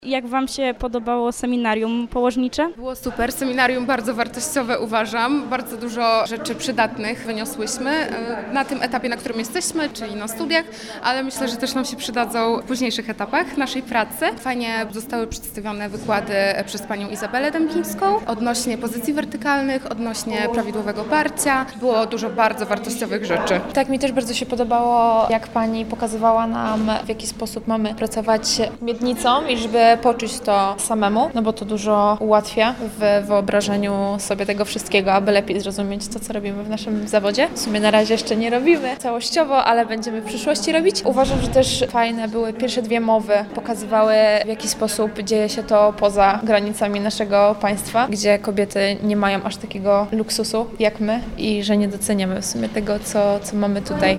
Nasze reporterki rozmawiały także ze studentkami, które przysłuchiwały się piątkowym prelekcjom.